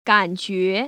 [gănjué] 깐쥐에